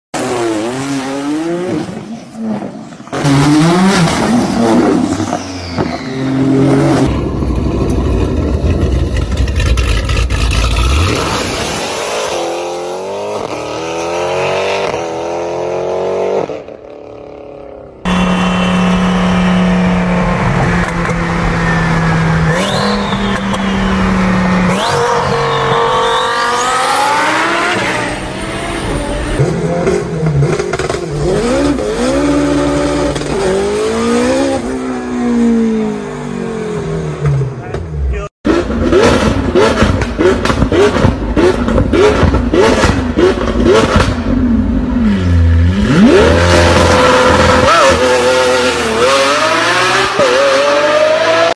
Top 5 1000+ HP Car Sound Effects Free Download